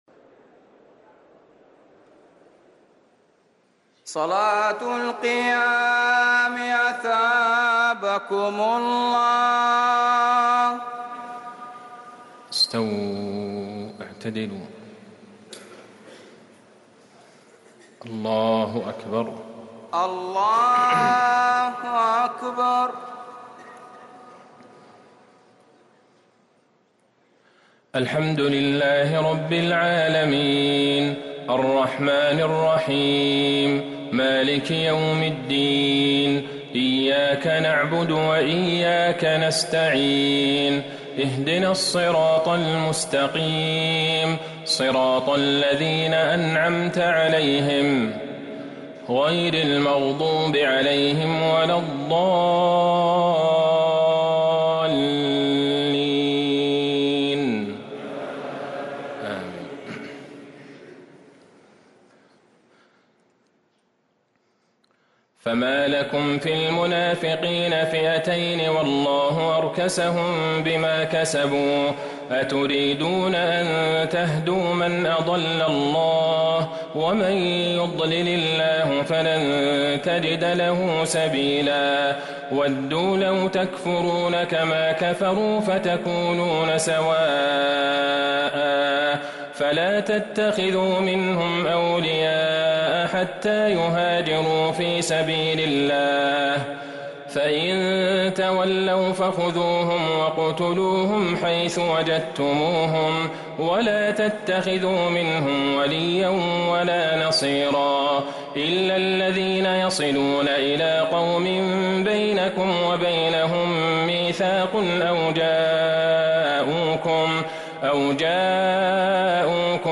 تراويح ليلة 7 رمضان 1444هـ من سورة النساء {88-134} Taraweeh 7st night Ramadan 1444H Surah An-Nisaa > تراويح الحرم النبوي عام 1444 🕌 > التراويح - تلاوات الحرمين